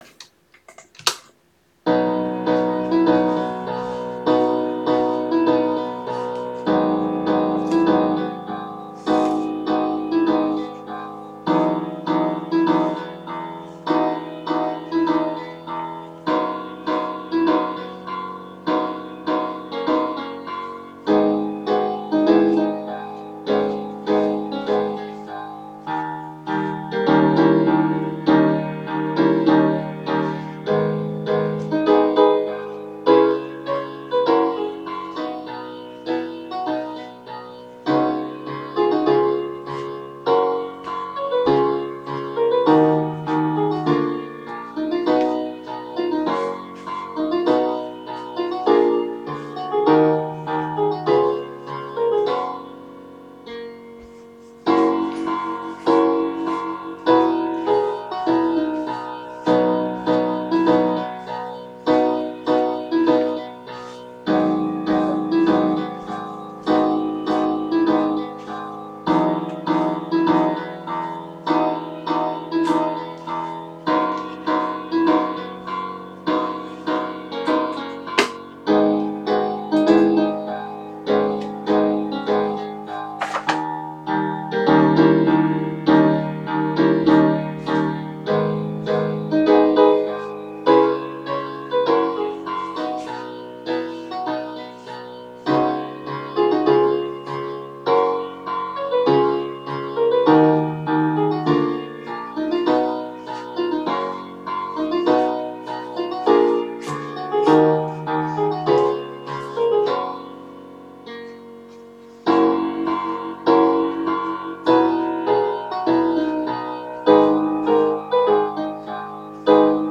Bridge Base Forever is in the key of B major, consisting of eight 8-bar verses. Its structure has the first three verses repeated – an “A-B-C-A-B-C-D-E” pattern.
It means that you pronounce it in two syllables: as “vpev́ǝ”.